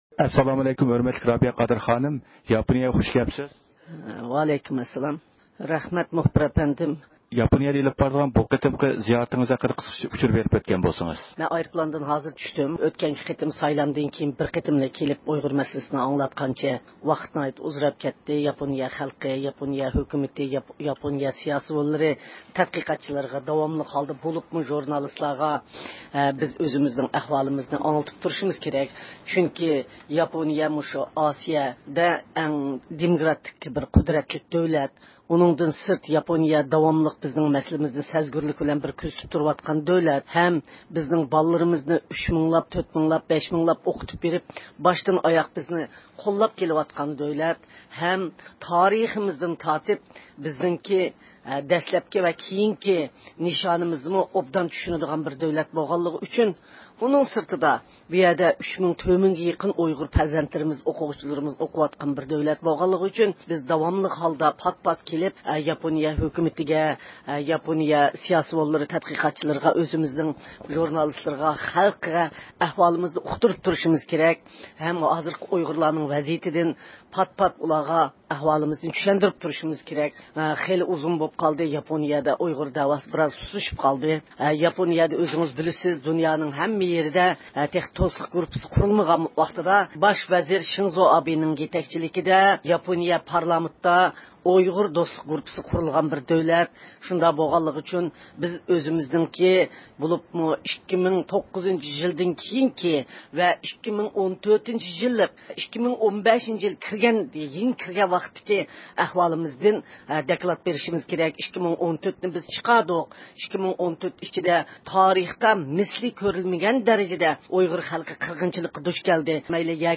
د ئۇ ق رەئىسى رابىيە قادىر خانىم نارىتا ئايرودرومدا رادىئومىزنىڭ زىيارىتىنى قوبۇل قىلدى. 2015-يىلى 28-يانۋار، توكيو.
دىققىتىڭلار، رابىيە قادىر خانىم بىلەن توكيو نارىتا ئايرودرومىدا ئېلىپ بارغان سۆھبىتىمىزدە بولغاي.